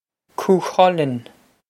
Pronunciation for how to say
Koo-khul-un
This is an approximate phonetic pronunciation of the phrase.